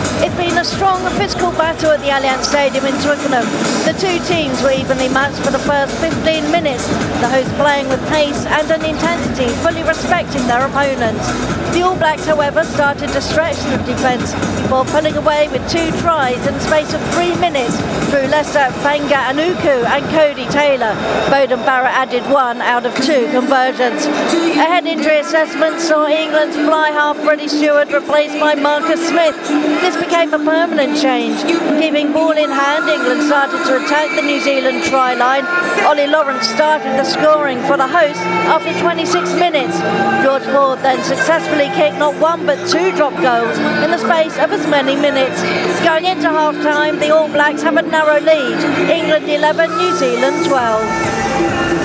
With the first half action from the Allianz Stadium in Twickenham